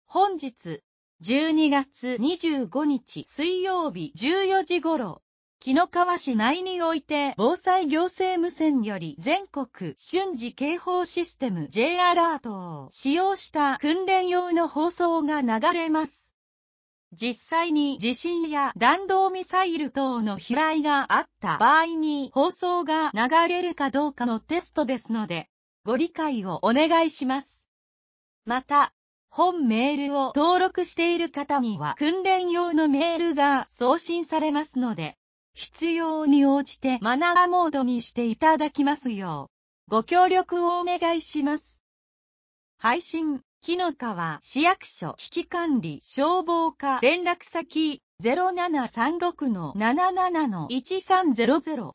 本日、１２月２５日（水）１４時頃、紀の川市内において防災行政無線より全国瞬時警報システム（Ｊアラート）を使用した訓練用の放送が流れます。実際に地震や弾道ミサイル等の飛来があった場合に放送が流れるかどうかのテストですので、ご理解をお願いします。